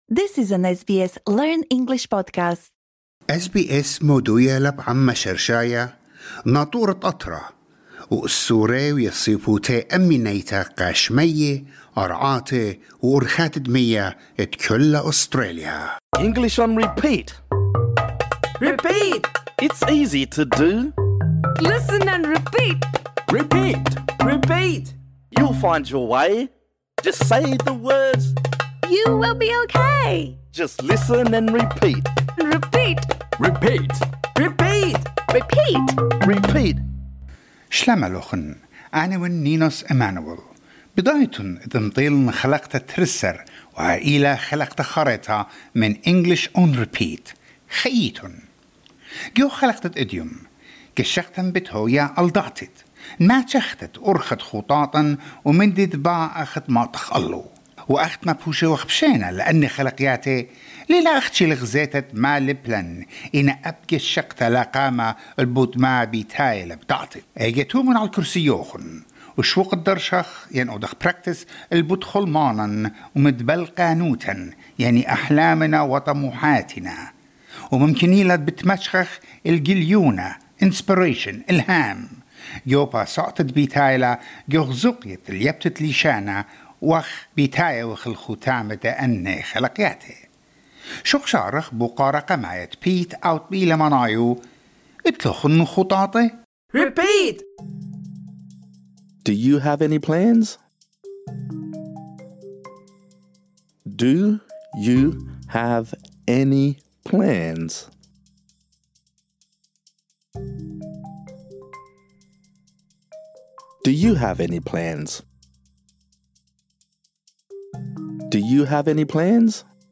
This lesson is designed for easy-level learners. In this episode, we practise saying the following phrases: Do you have any plans?